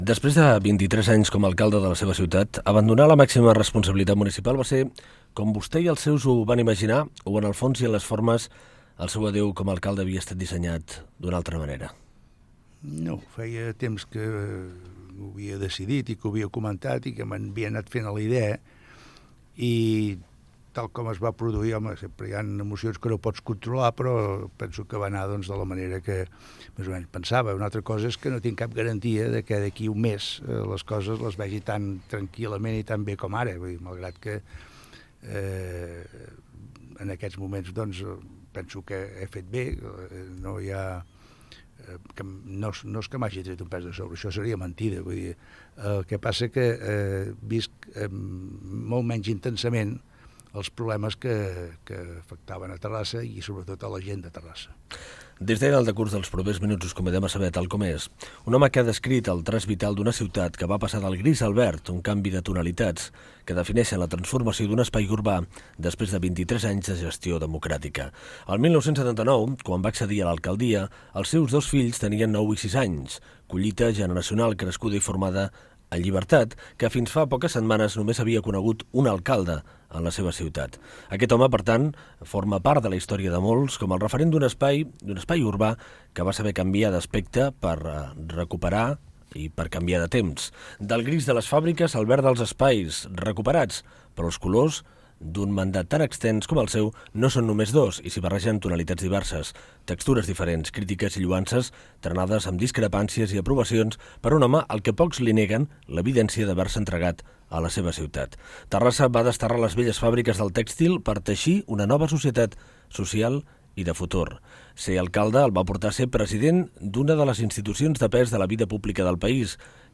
Fragment d'una entrevista al polític Manuel Royes, president de la Diputació de Barcelona, després de deixar l'alcaldia de Terrassa.